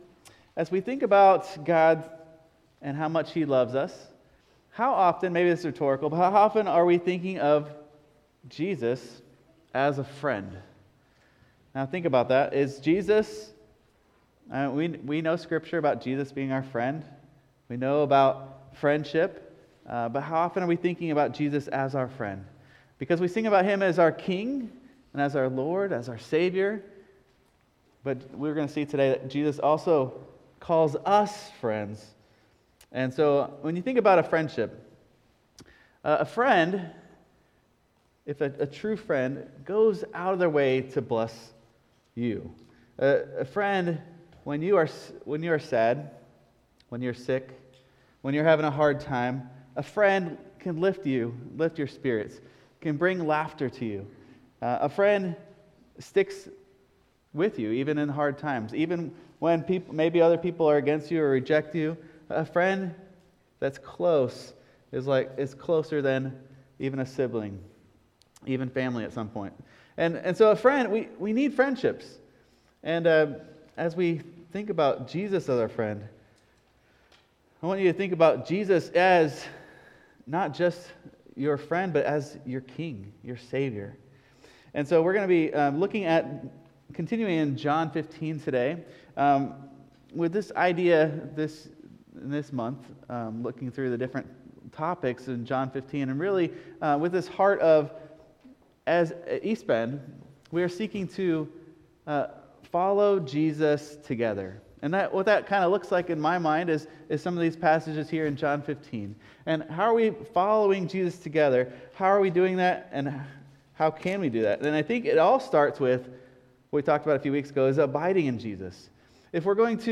… continue reading 292 odcinków # Religion # Sunday Service # East Bend Mennonite Church # Christianity